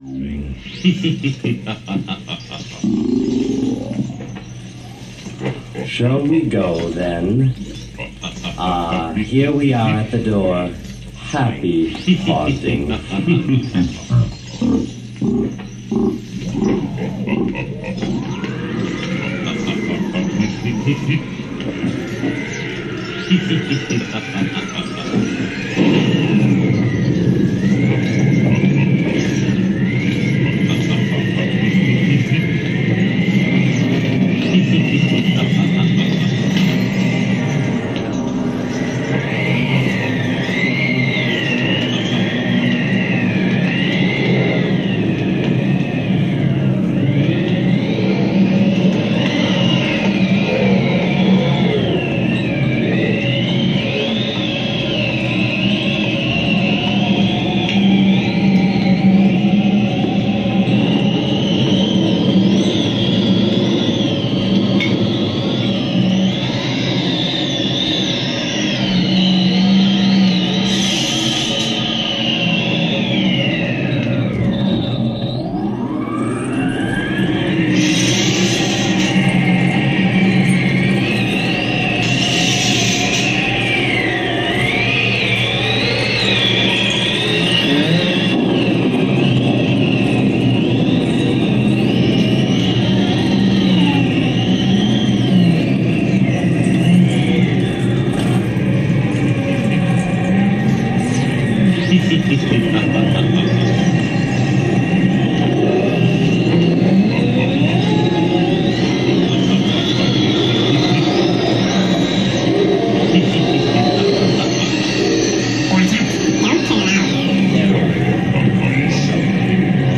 声は使わず、オルゴール、ターンテーブル、そして7つの発振器を内蔵するシドラッシ・オルガンを用いている。